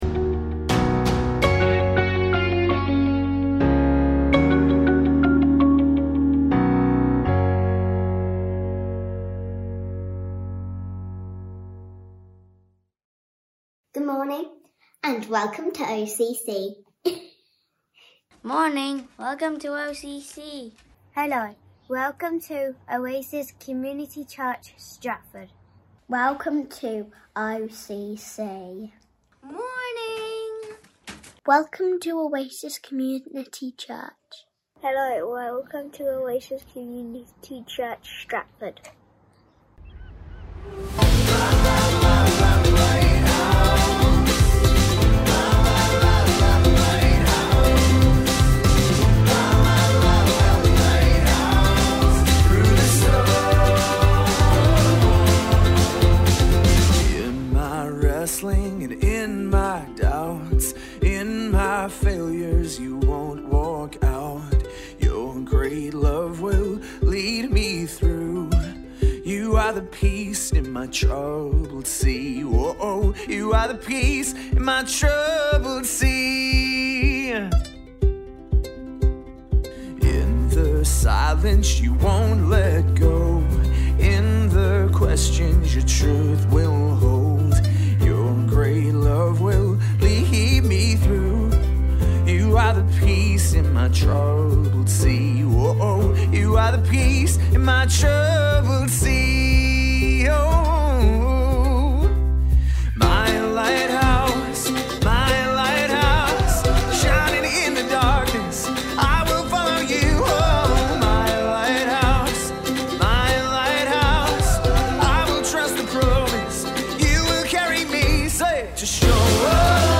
Listen again to our online gathering as we worship together and gather around what it means for us to consecrate ourselves as we look at 1 Peter 1:3-9.